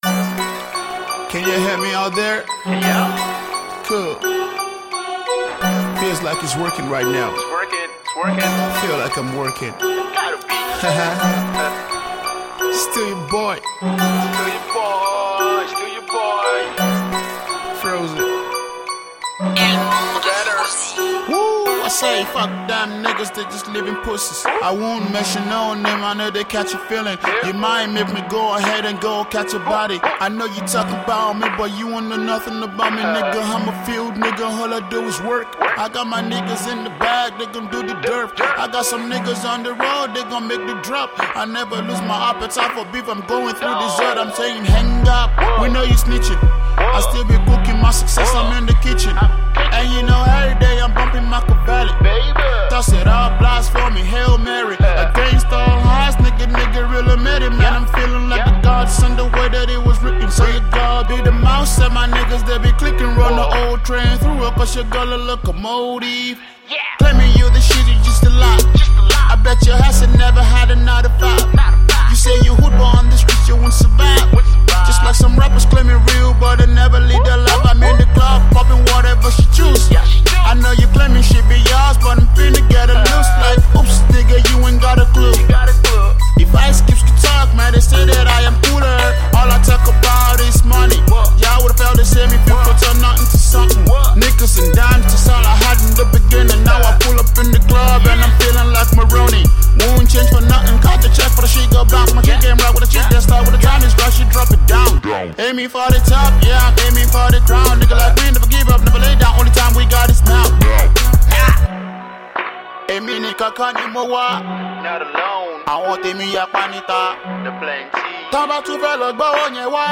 its a Hard Rap song
over a sharp instrumental